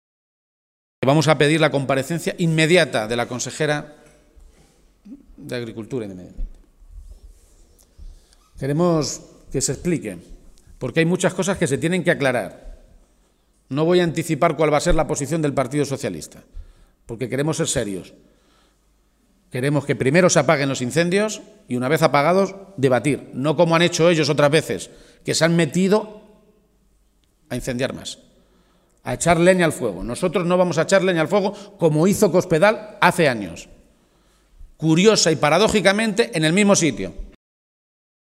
El líder de los socialistas castellano-manchegos hacía estas manifestaciones en la capital conquense, donde denunció además que “Cospedal se ceba particularmente con Cuenca” a través de su política de recortes en sanidad, educación y servicios sociales.